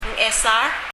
もうひとり、ネイティブなパラワンのNgサウンドを聞いてみましょう。
彼女の場合、あまりNgを意識的に発音しないようなのですが、たまたまNgサウンドが聞き取れたケースがありました。
発音
Ngchesar（エサール）の場合は、Ng と e の間にある ch がクセモノ、ということなんでしょう。CHサウンドがあることで、Ngサウンドがはっきり耳にとどくようにきこえた、ということかもしれません。